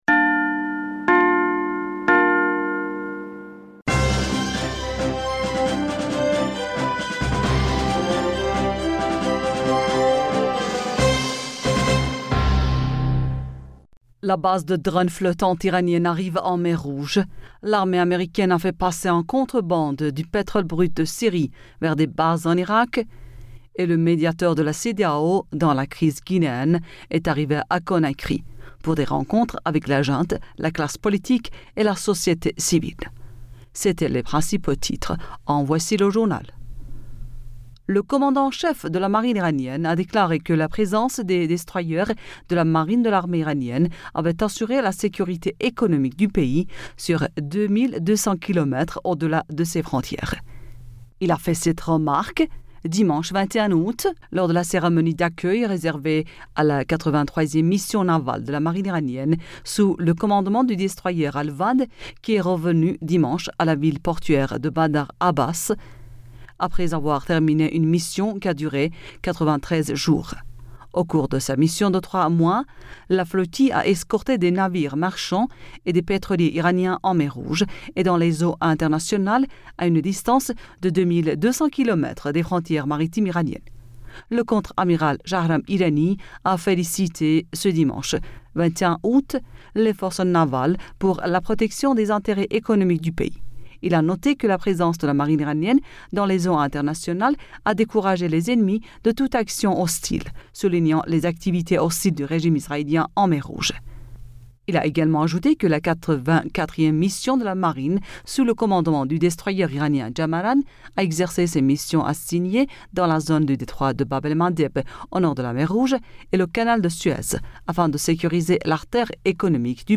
Bulletin d'information Du 22 Aoùt